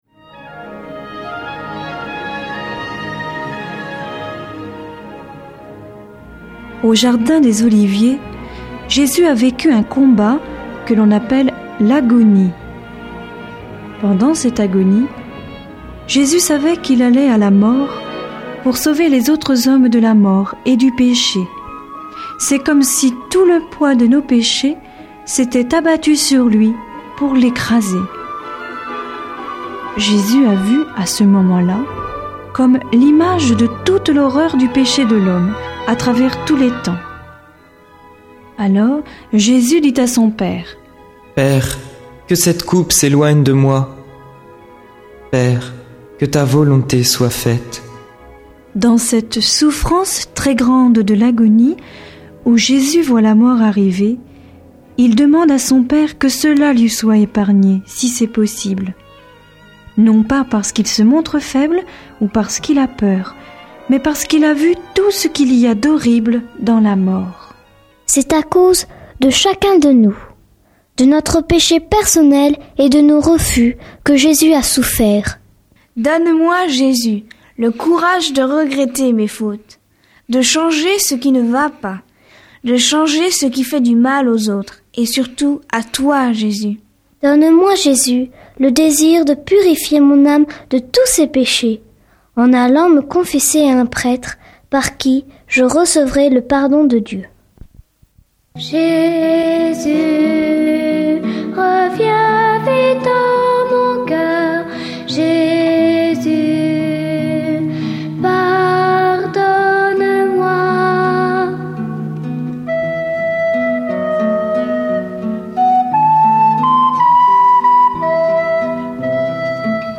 Le Rosaire nous apprend � d�couvrir l'Evangile avec Marie. Musiques, dialogues, explications...Intervenant(s